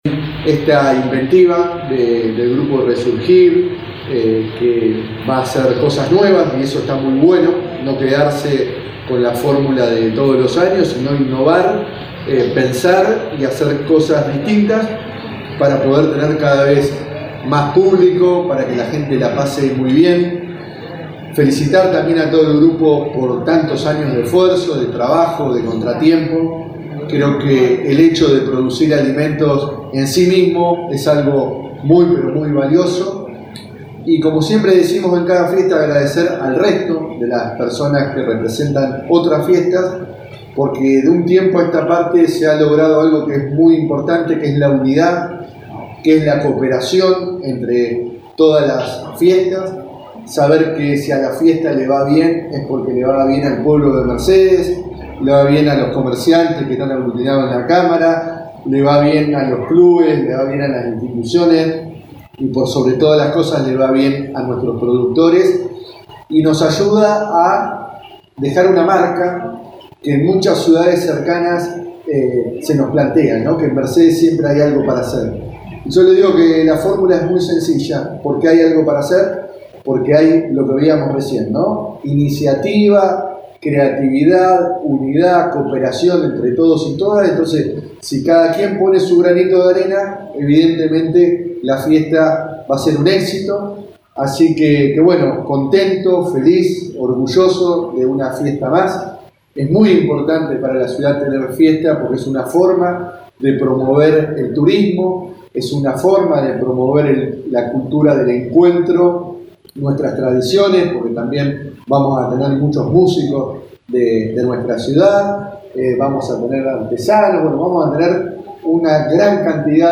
INTENDENTE USTARROZ EN RADIO UNIVERSO